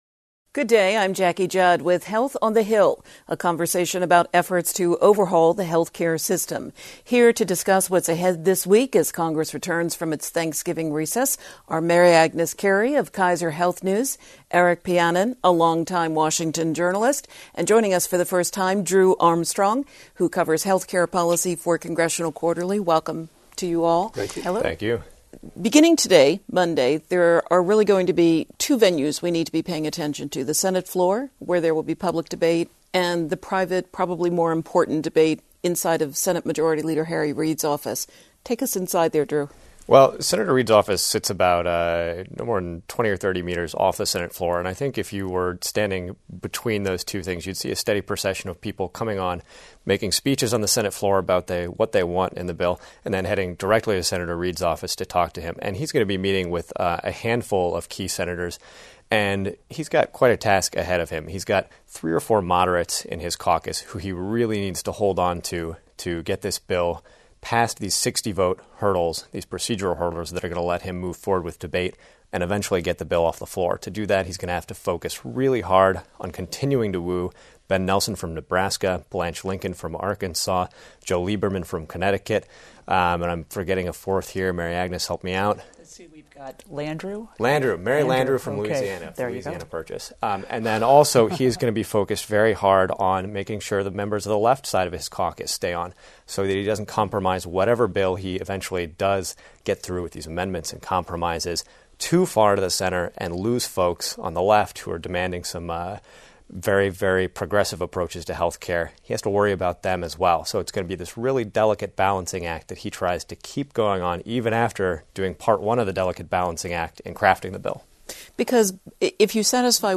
Panelists discuss the array of amendments Democrats and Republicans are expected to offer as the Senate begins debate on health overhaul legislation.